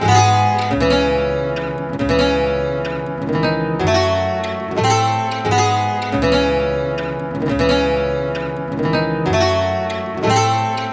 Corner Banjo.wav